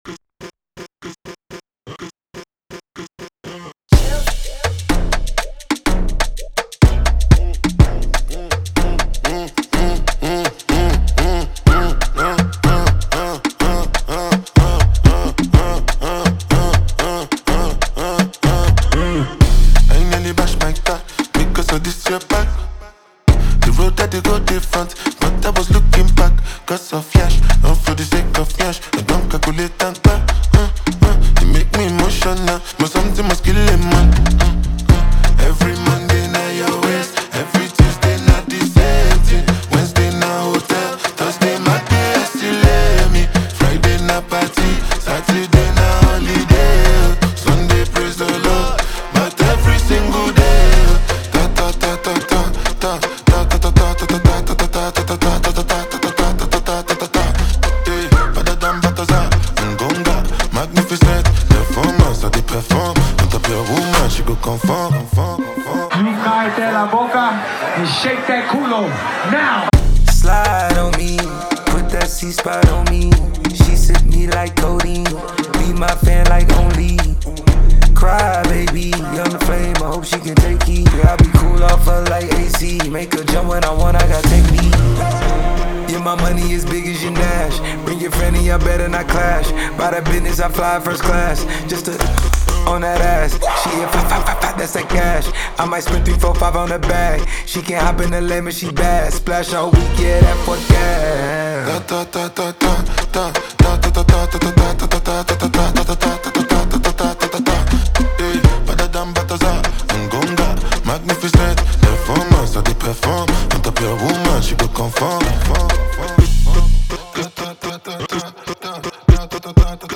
• Жанр: Hip-Hop, R&B